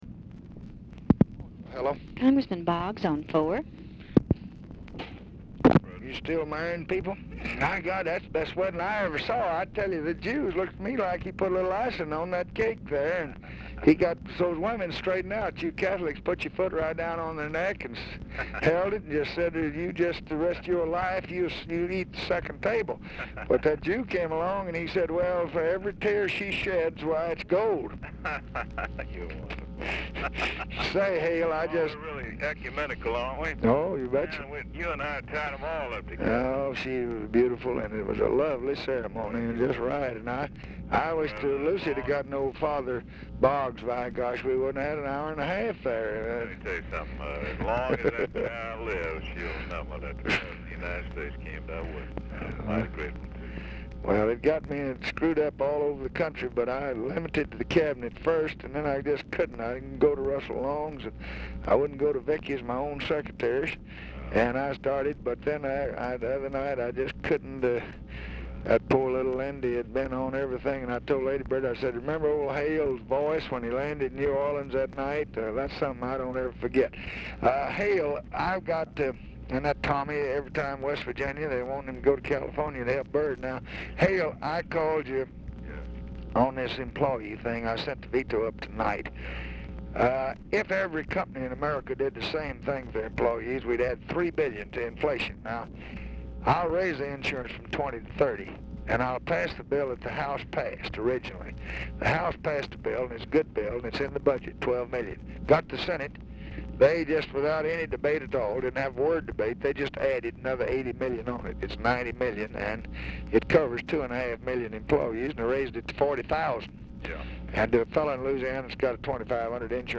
Telephone conversation # 10756, sound recording, LBJ and HALE BOGGS, 9/12/1966, 8:25PM | Discover LBJ
Format Dictation belt
Location Of Speaker 1 Oval Office or unknown location
Specific Item Type Telephone conversation